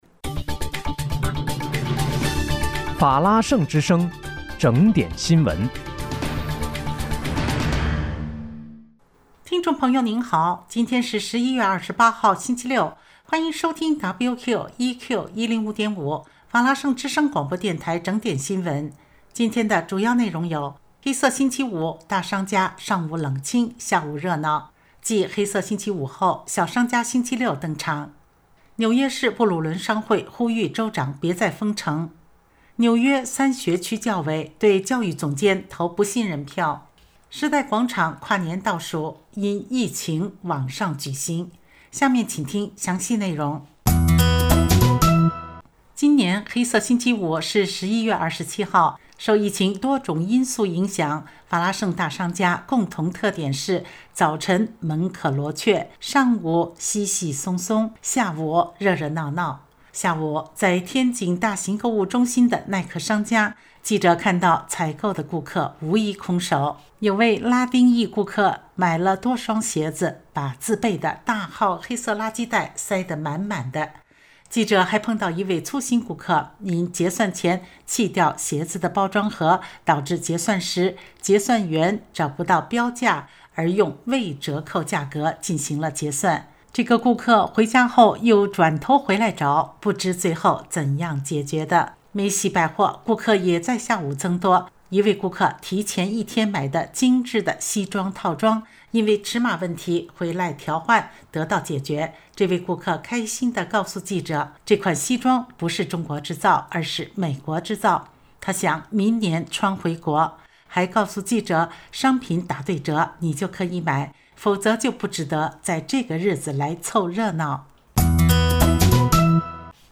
11月28日（星期六）纽约整点新闻